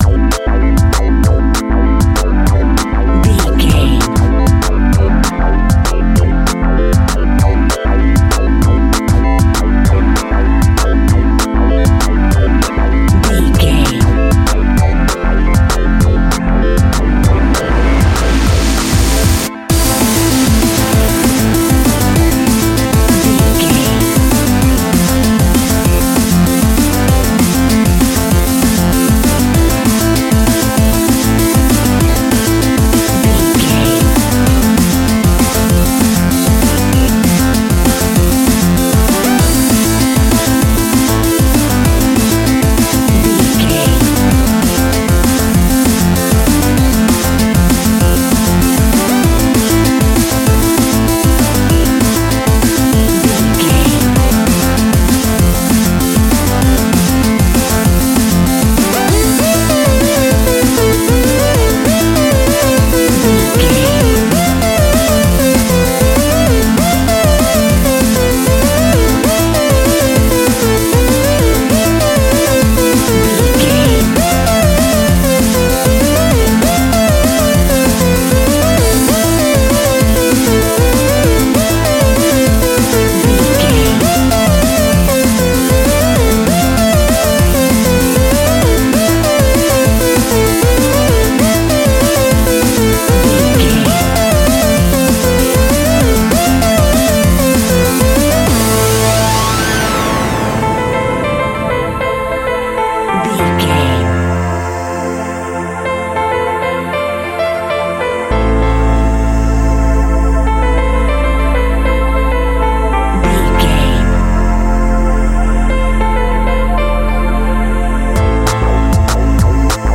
Drum and Bass Fast and Powerful.
Epic / Action
Fast paced
Aeolian/Minor
aggressive
dark
driving
frantic
energetic
intense
futuristic
drum machine
synthesiser
electronic
sub bass
Neurofunk
synth leads
synth bass